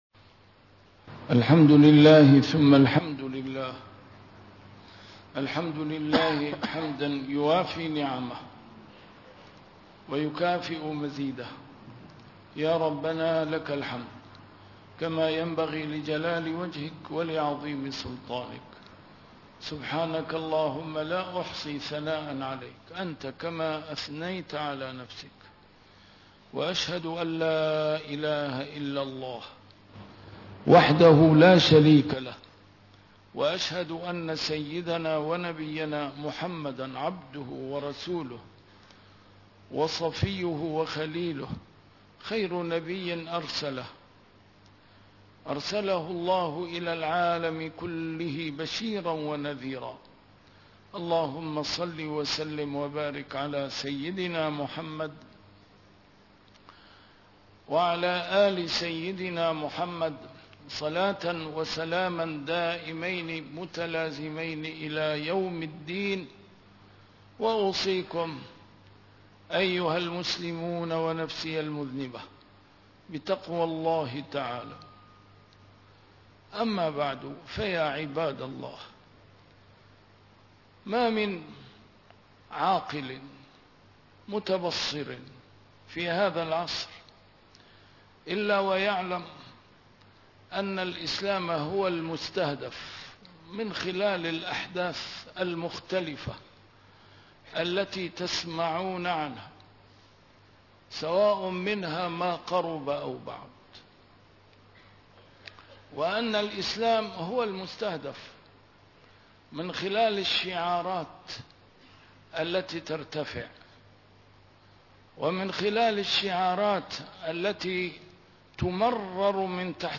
نسيم الشام › A MARTYR SCHOLAR: IMAM MUHAMMAD SAEED RAMADAN AL-BOUTI - الخطب - الأسافين البريطانية لتفتيت الوحدة الإسلامية